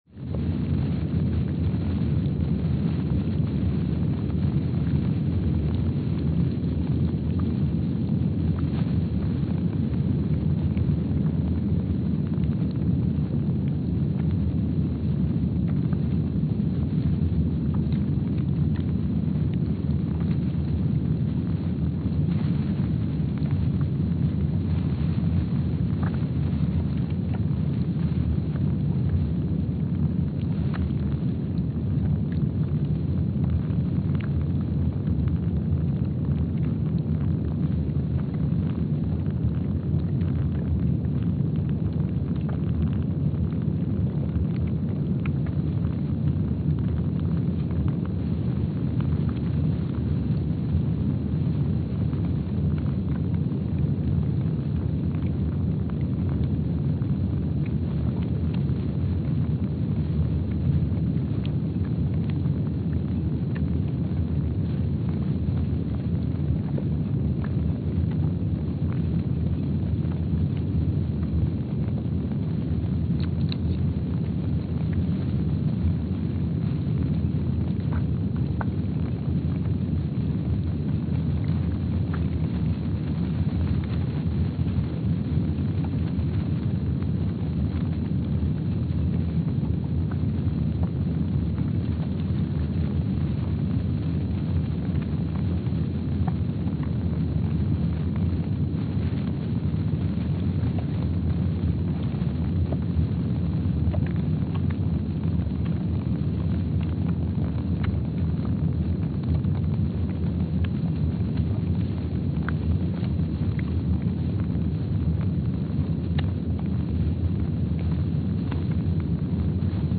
Palmer Station, Antarctica (seismic) archived on March 19, 2025
Sensor : STS-1VBB_w/E300
Speedup : ×500 (transposed up about 9 octaves)
Loop duration (audio) : 05:45 (stereo)
Gain correction : 25dB